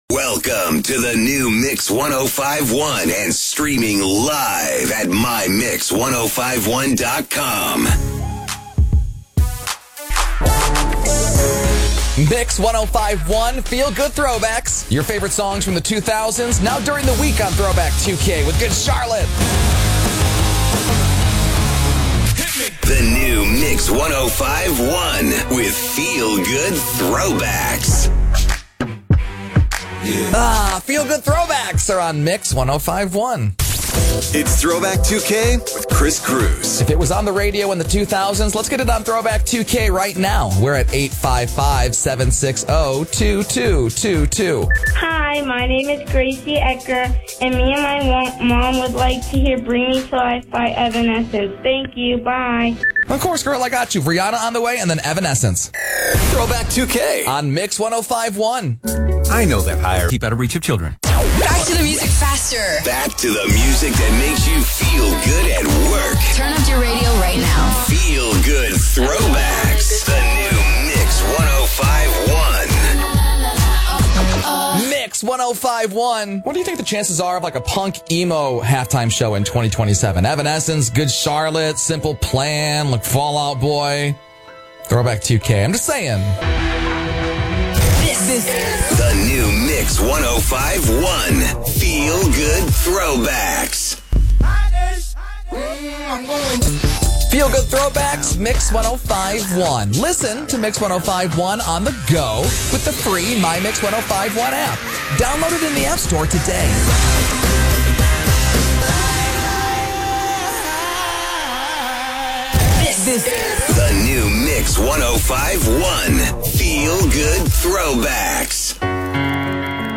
THROWBACK 2K® DAILY – one hour of 2K Throwbacks per day (as heard on KKRG/Albuquerque)